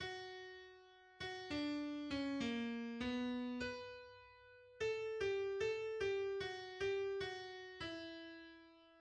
Metamorphosen, en français Métamorphoses, est une œuvre écrite pour 23 instruments à cordes par Richard Strauss et achevée le .
Les 23 instruments sont 10 violons, 5 altos, 5 violoncelles et 3 contrebasses, soit 5 quatuors à cordes et 3 contrebasses.
Elle est constituée en fait d'un vaste adagio avec une partie centrale plus tourmentée.